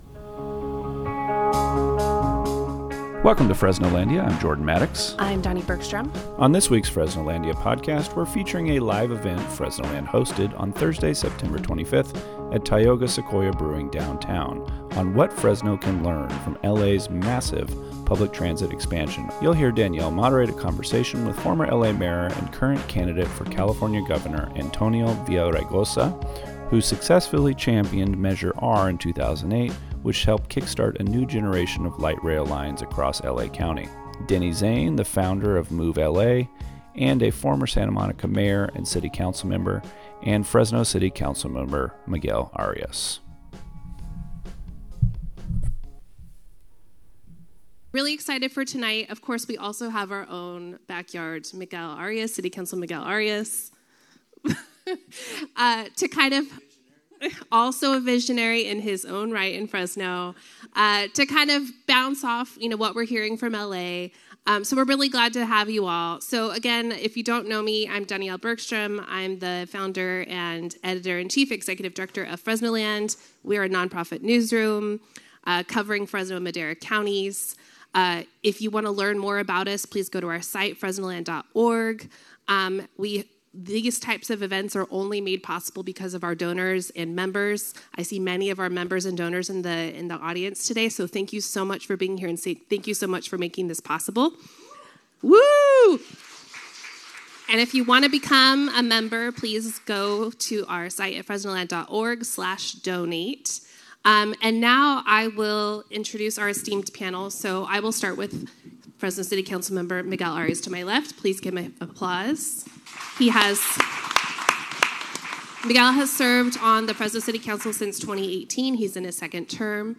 On this week's Fresnolandia podcast, we're featuring a live event Fresnoland hosted on Thursday, September 25 at Tioga Sequoia Brewing downtown on what Fresno can learn from LA's massive public transit expansion over the last decade.